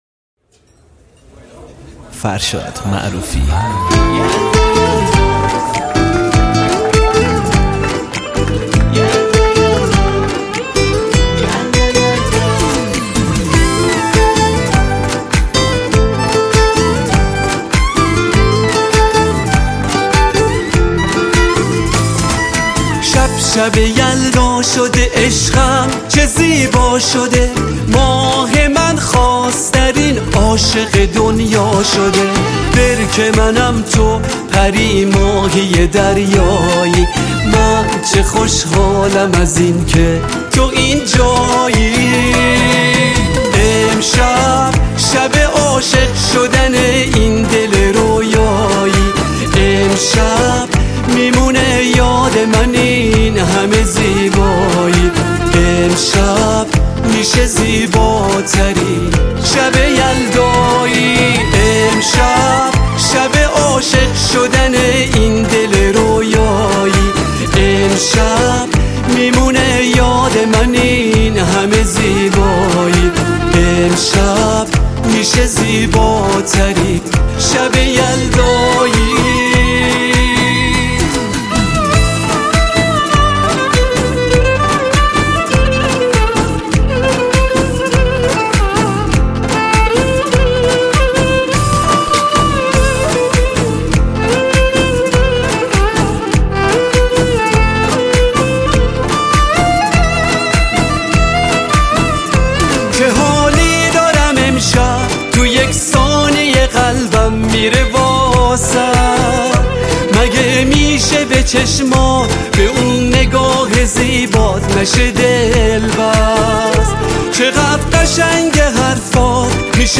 موسیقی
آهنگهای پاپ فارسی